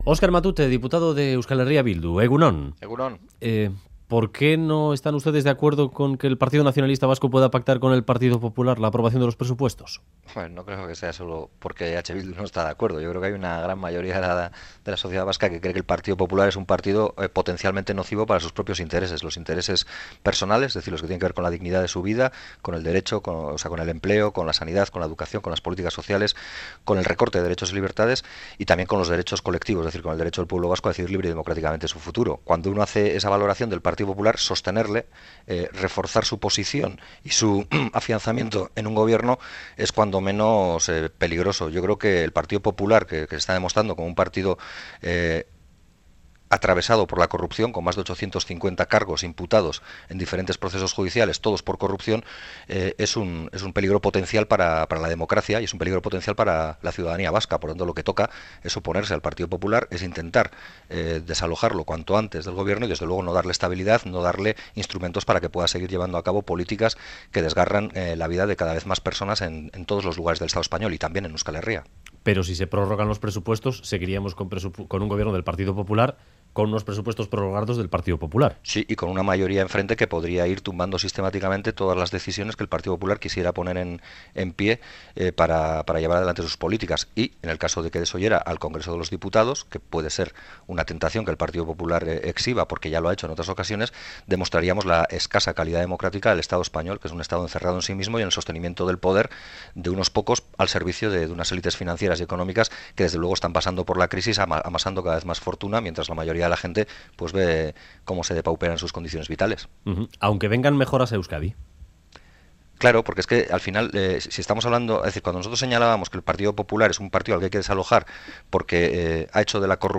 Radio Euskadi BOULEVARD 'Un apoyo a los PGE supondría un contrato de permanencia con el Estado' Última actualización: 03/05/2017 10:07 (UTC+2) Entrevista al diputado de EH Bildu Oskar Matute en el programa Boulevard de Radio Euskadi .